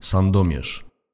Sandomierz (pronounced: [sanˈdɔmjɛʂ]
Pl-Sandomierz.ogg.mp3